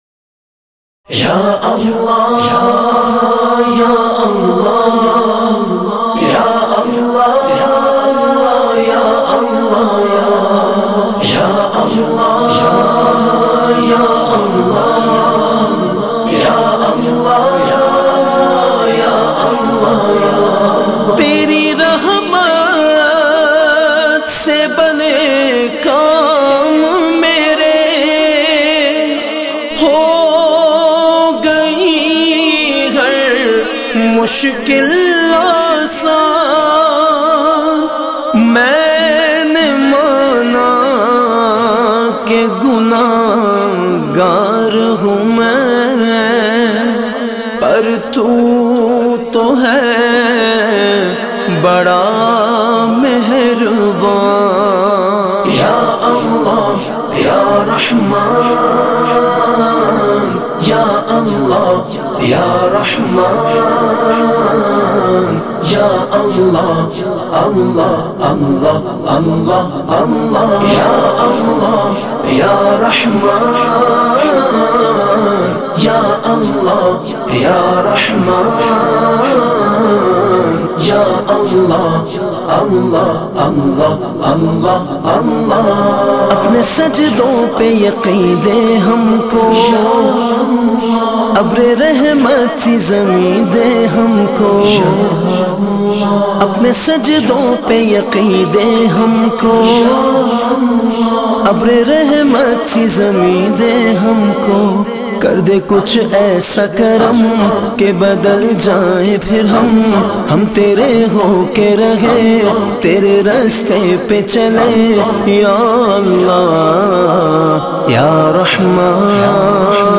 naat khuwan